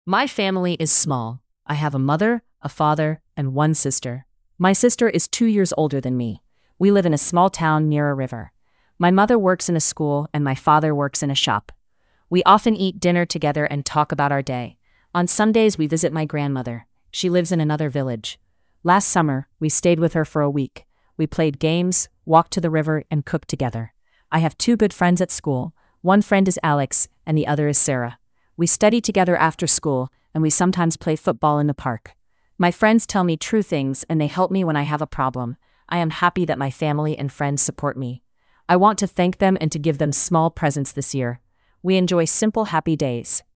Listening comprehension